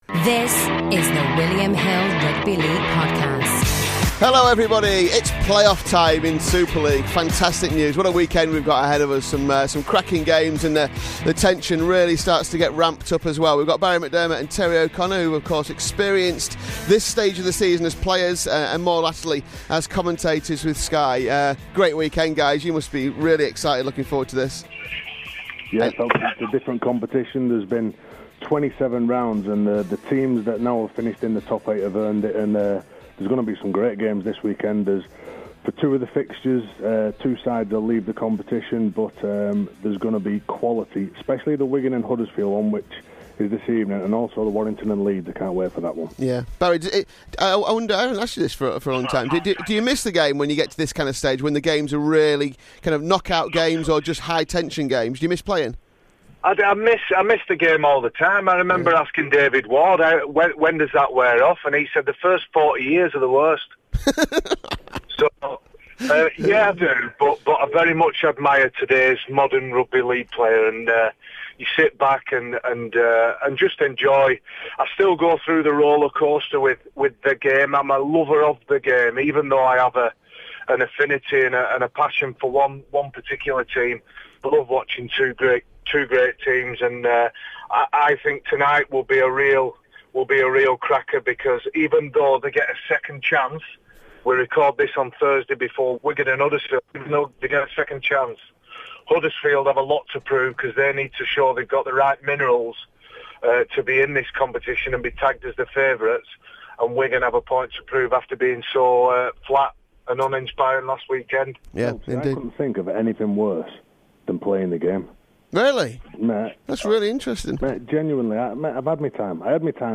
Two men who have experienced it help William Hill punters pick out the best bets over the three days.